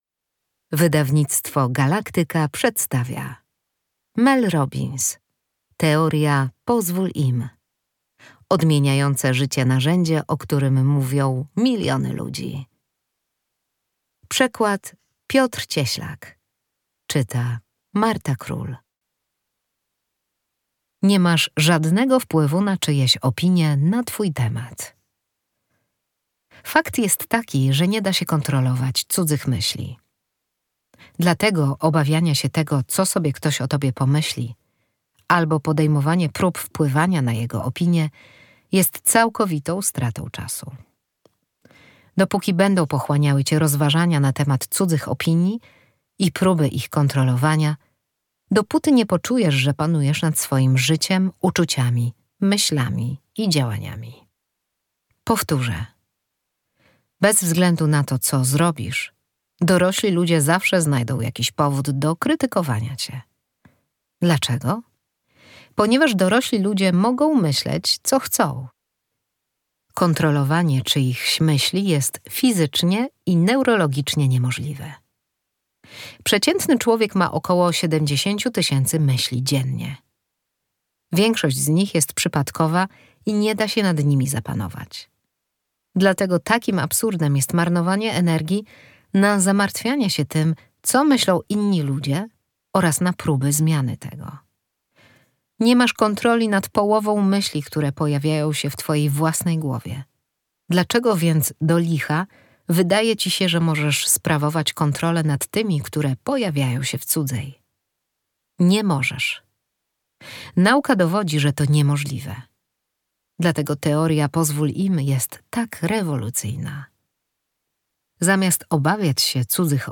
fragment książki:
Audiobook MP3